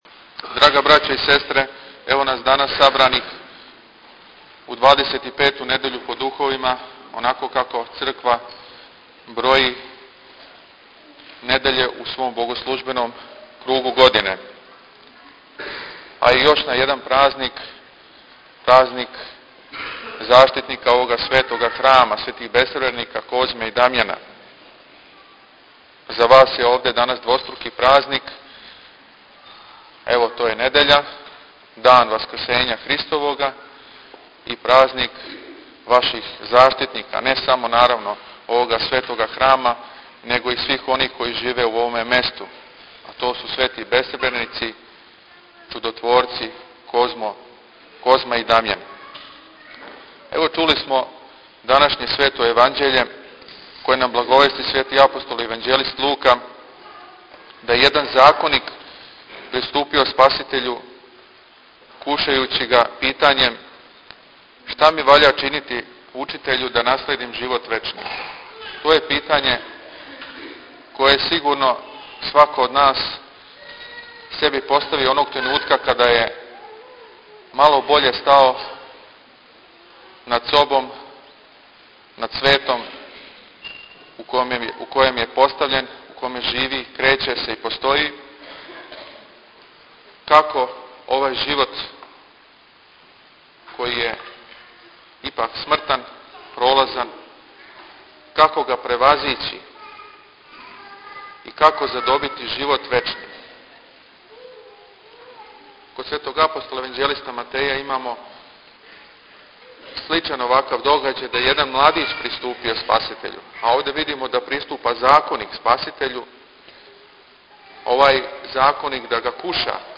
• Беседа